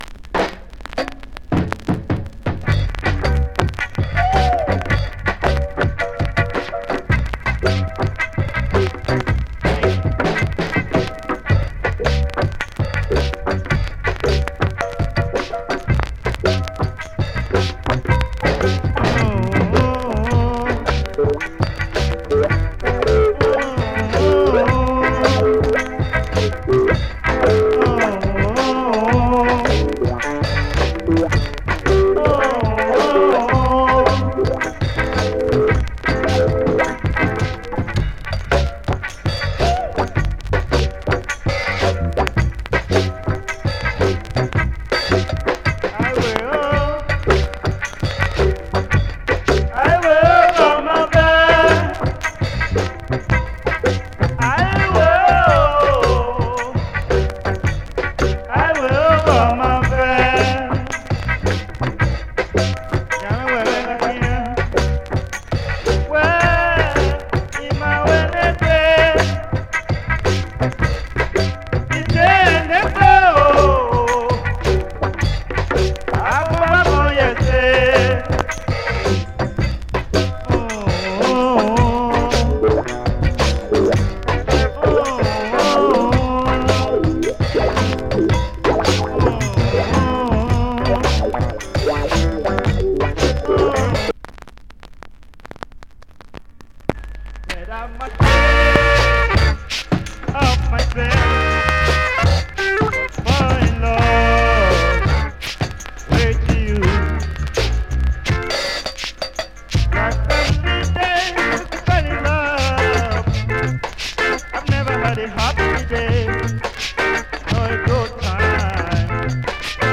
Genre:             Reggae, Funk / Soul